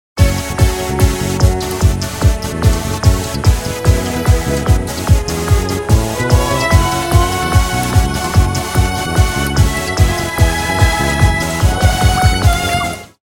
رینگتون نرم و بیکلام